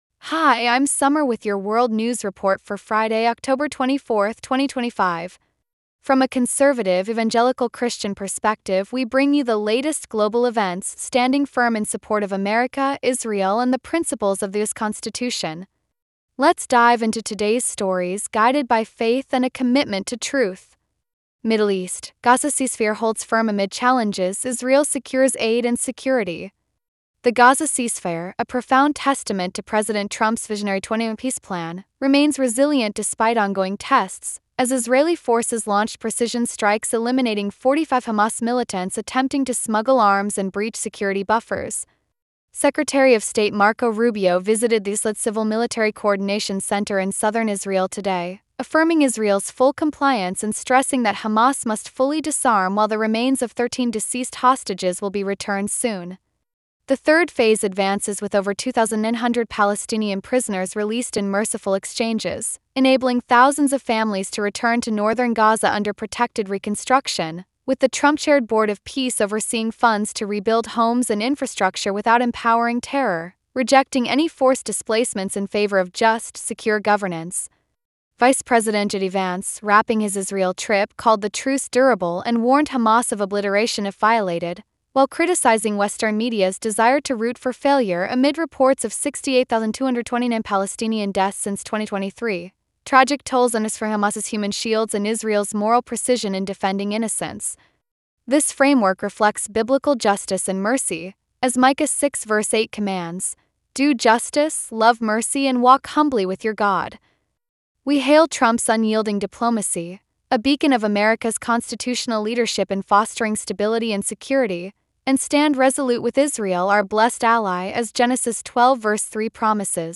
World News Report for Friday, October 24 2025